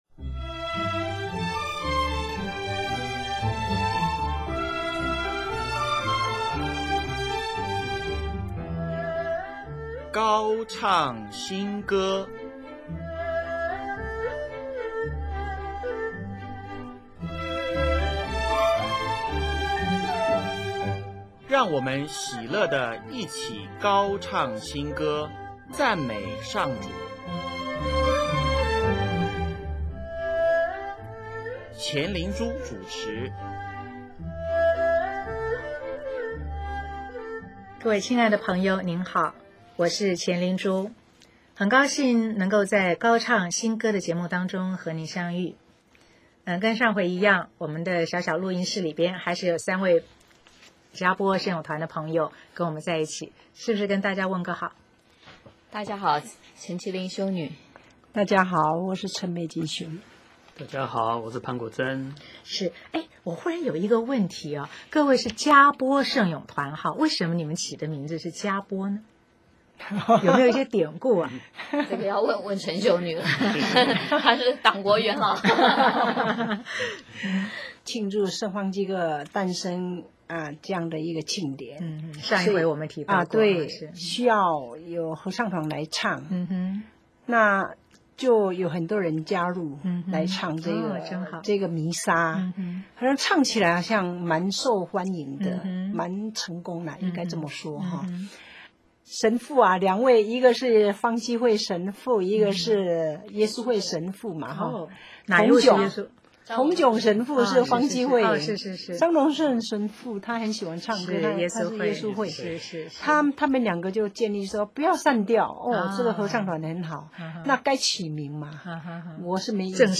【高唱新歌】8|专访“佳播圣咏团”(四)：不吝于赞美天主